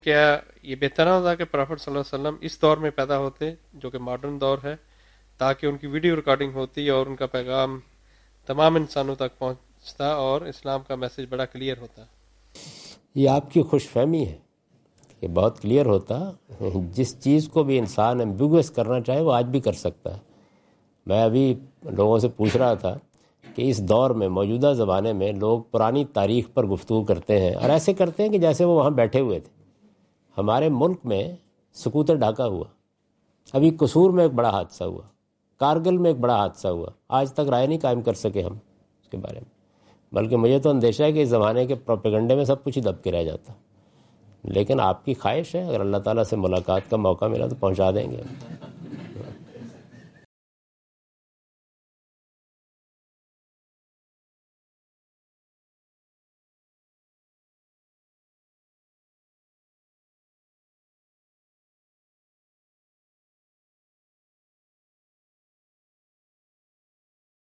Javed Ahmad Ghamidi answer the question about "need of a prophet in the present times" during his Australia visit on 11th October 2015.